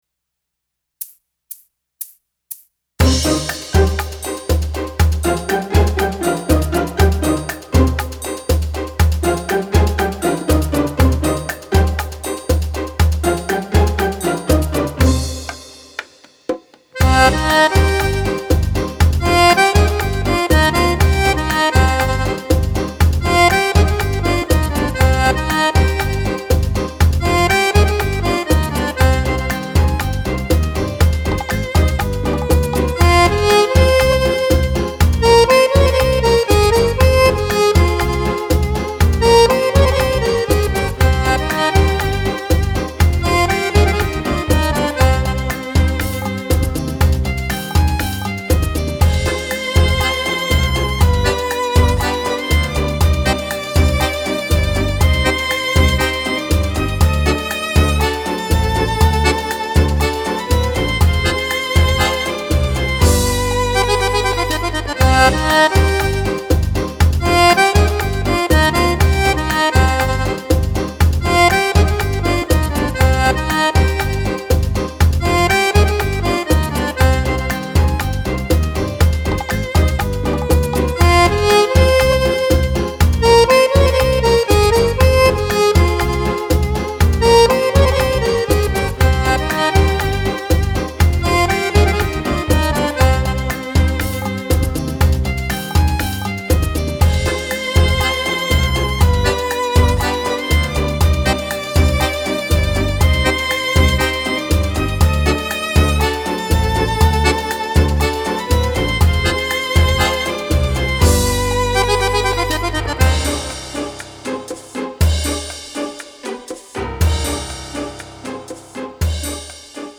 Bachatango - Strumentale